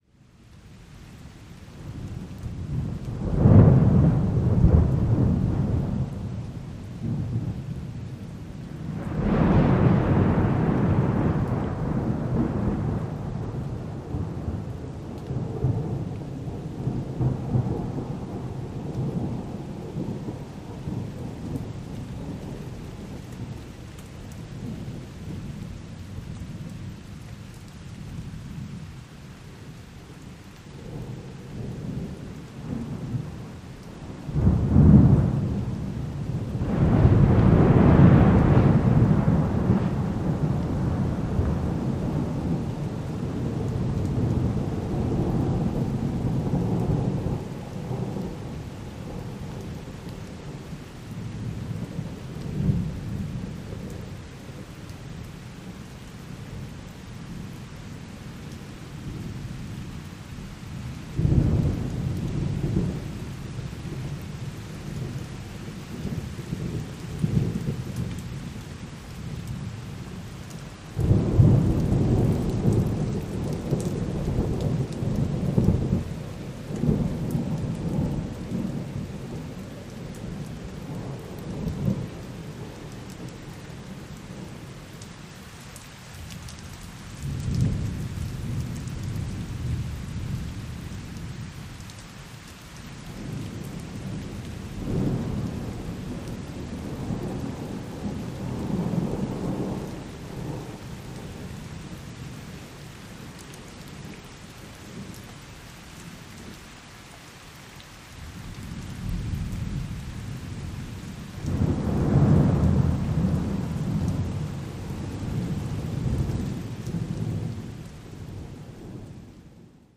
دانلود آهنگ باران به همراه صدای رعد و برق از افکت صوتی طبیعت و محیط
دانلود صدای باران به همراه صدای رعد و برق از ساعد نیوز با لینک مستقیم و کیفیت بالا
جلوه های صوتی